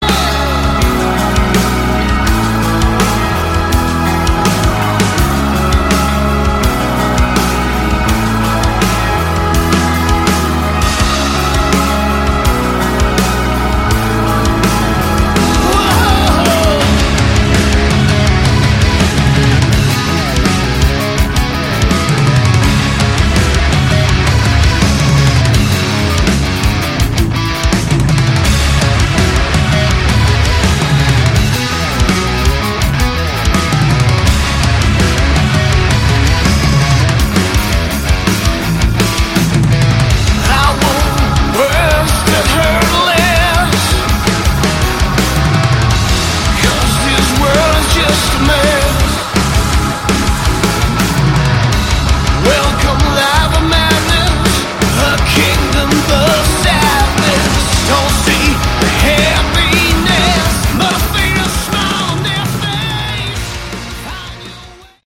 Category: Melodic Metal
guitars
vox
keyboards
bass
drums